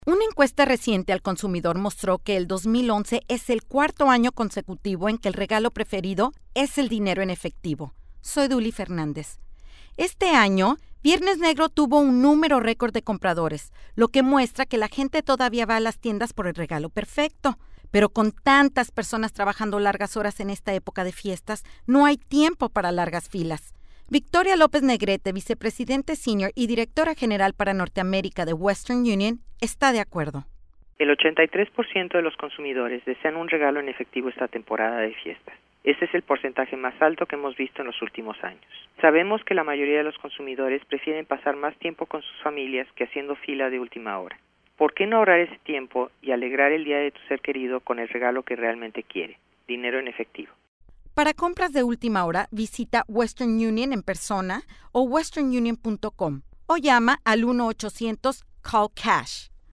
December 15, 2011Posted in: Audio News Release